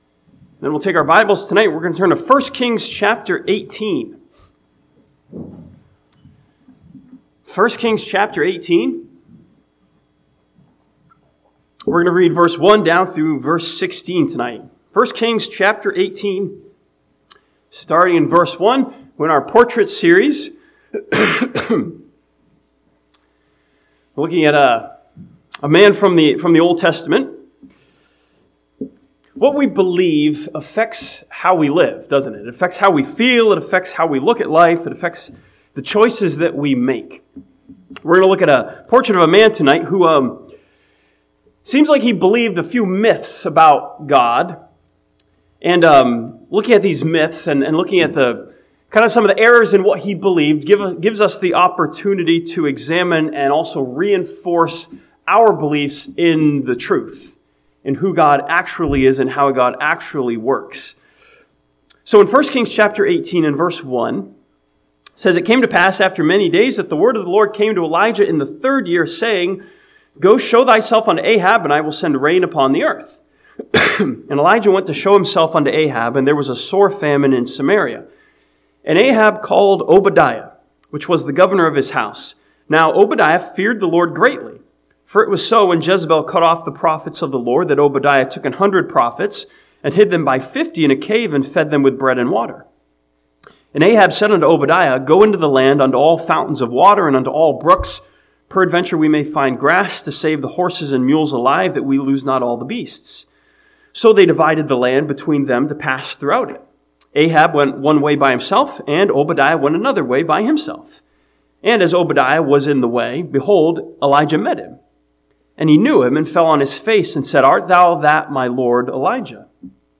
This sermon from 1 Kings chapter 18 studies Obadiah as a portrait of a man who believed several myths about God.